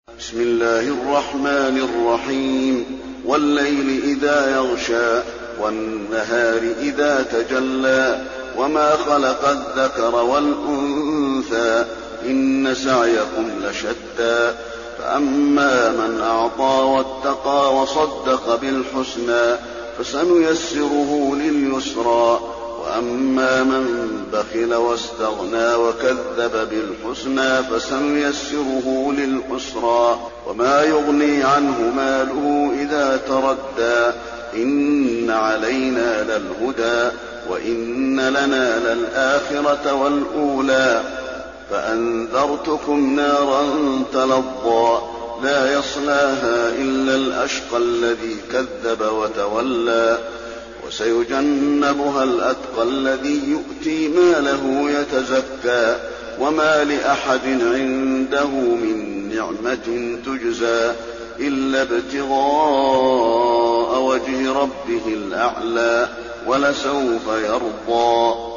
المكان: المسجد النبوي الليل